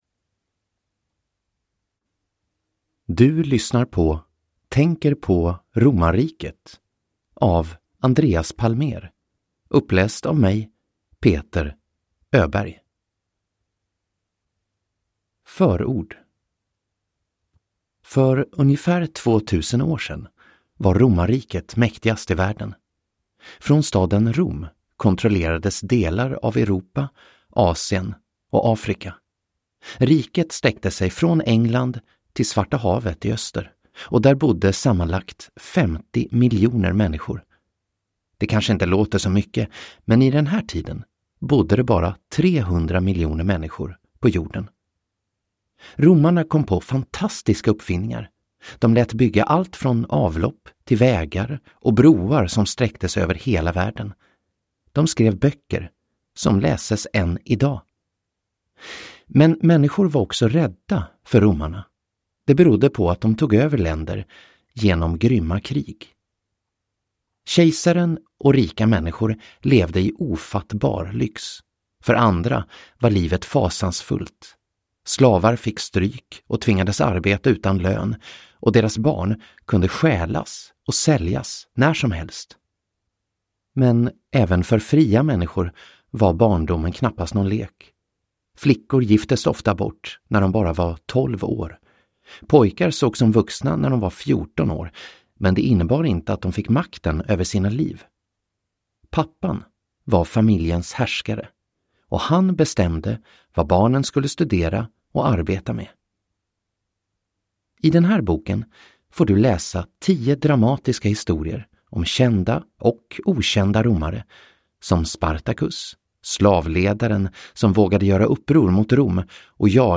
Romarriket – Ljudbok